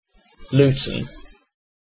Ääntäminen
Ääntäminen UK : IPA : /luːtən/ Haettu sana löytyi näillä lähdekielillä: englanti Käännöksiä ei löytynyt valitulle kohdekielelle.